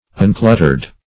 uncluttered - definition of uncluttered - synonyms, pronunciation, spelling from Free Dictionary